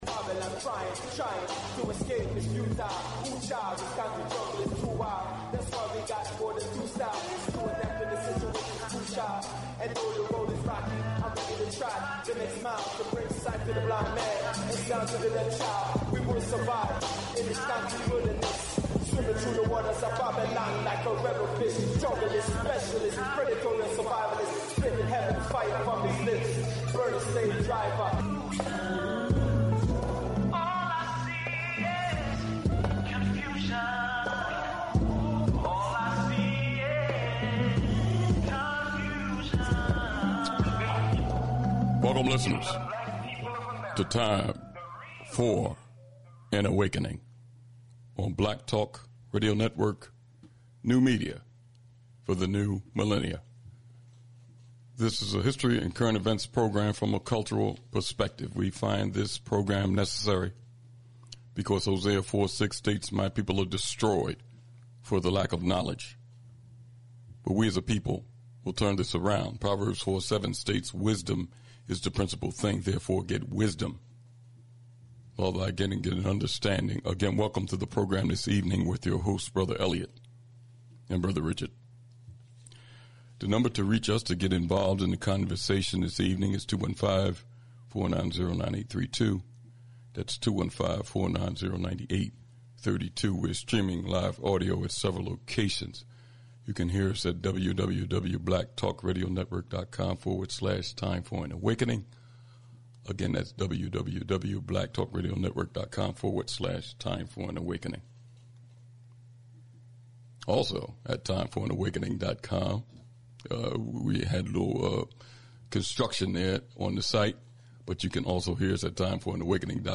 Also, part of the dialogue with our guest will include conversations about recent military actions by the West, which could spark a Global conflict and the struggle for Global African Reparations . Always conversations on topics that affect Black people locally, nationally, and internationally.